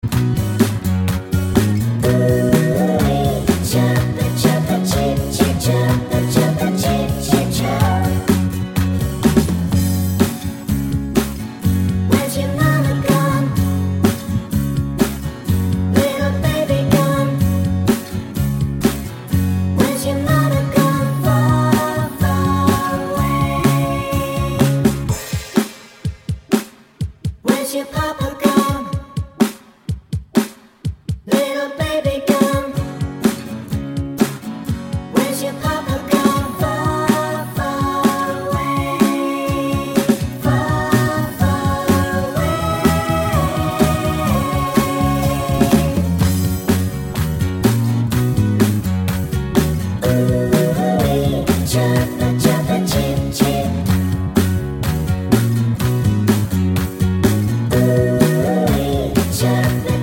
Two Semitones Up Pop (1970s) 2:59 Buy £1.50